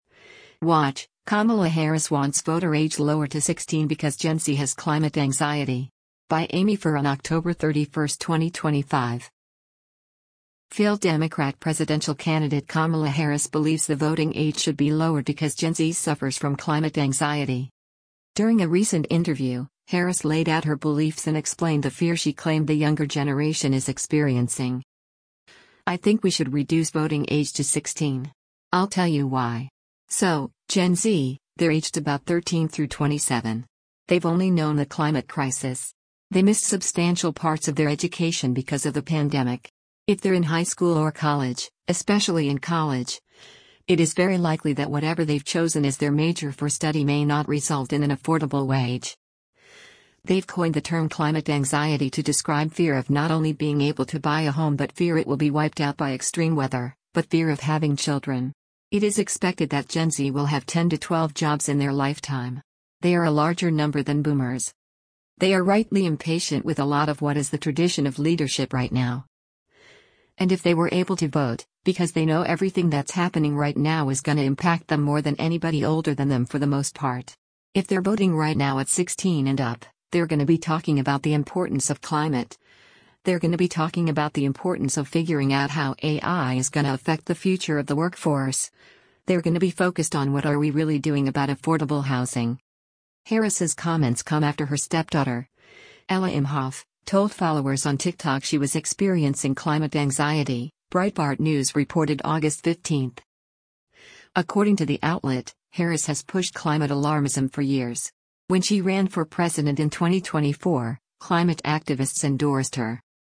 During a recent interview, Harris laid out her beliefs and explained the “fear” she claimed the younger generation is experiencing: